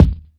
Break_kick_14.wav